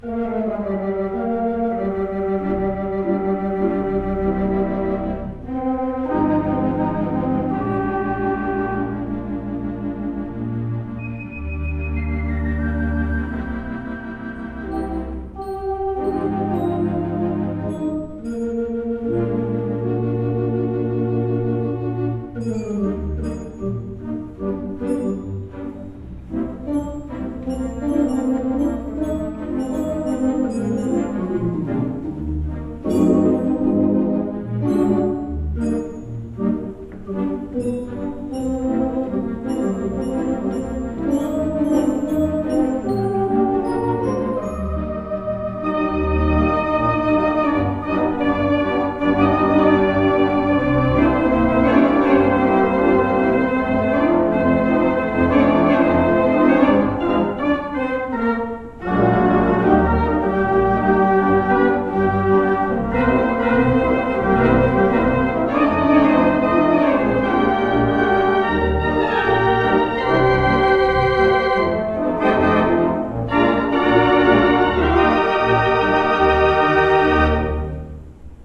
2/11 Mighty WurliTzer Theatre Pipe Organ
Lafayette Theatre, Suffurn, New York